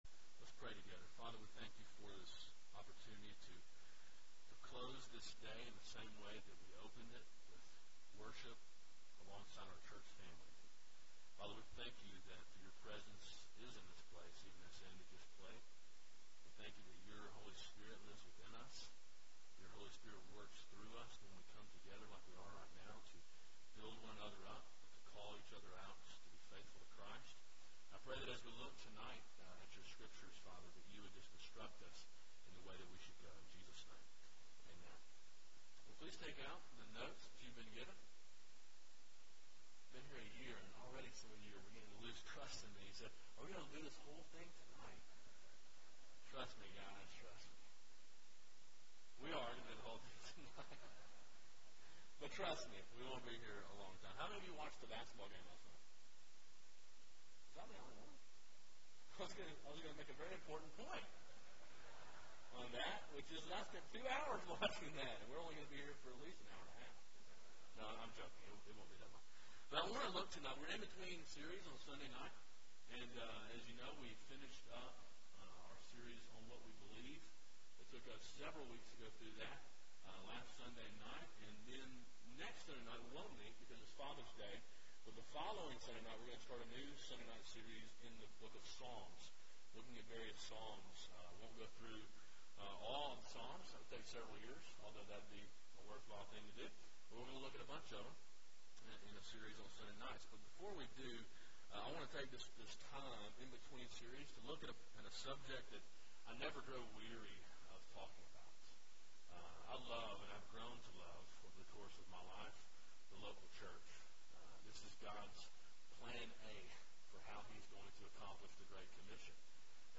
A sermon preached during the evening service on 6.10.12. Download mp3